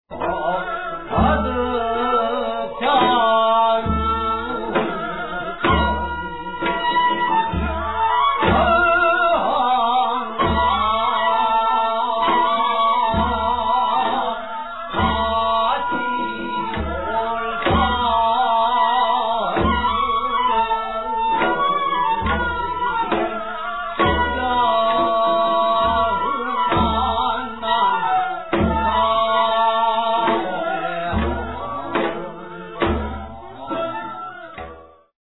Fascinating and diverse Korean traditional orchestral music.
Recorded in Seoul, Korea.